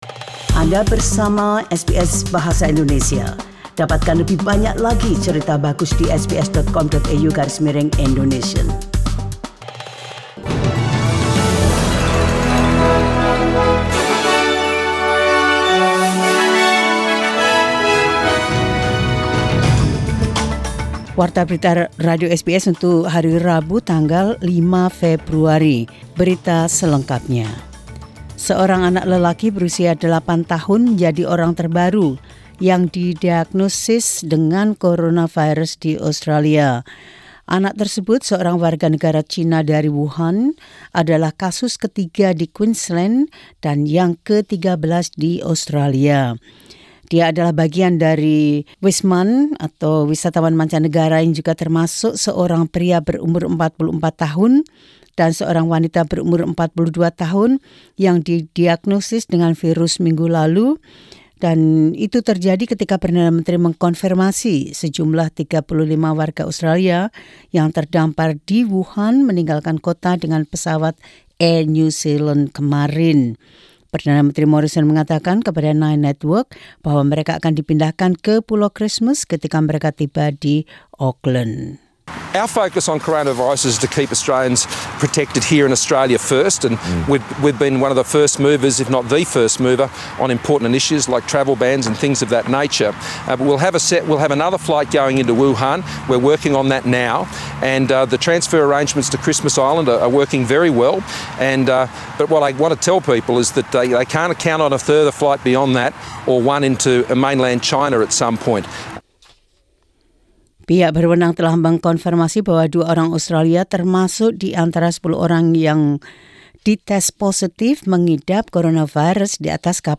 SBS Radio News in Indonesian 5 Feb 2020.